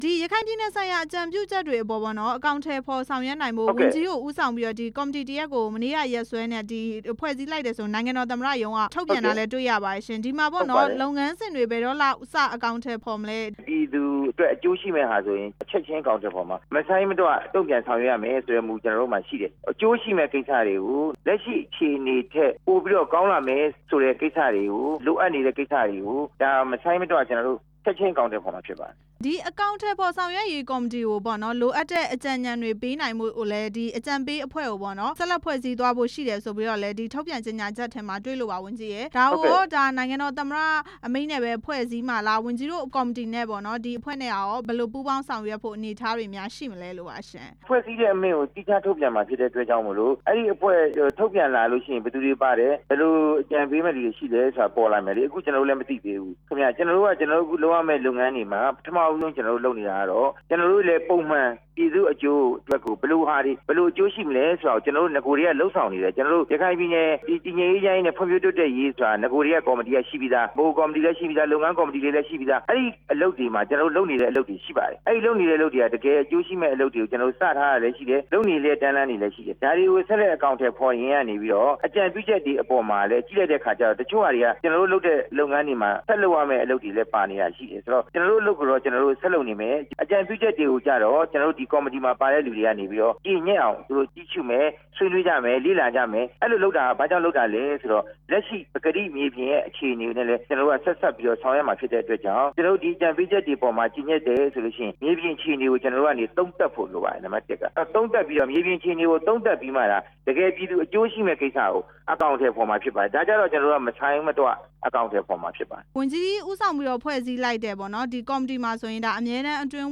ဆက်သွယ် မေးမြန်းထားပါတယ်။